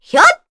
Veronica-Vox_Attack3_kr.wav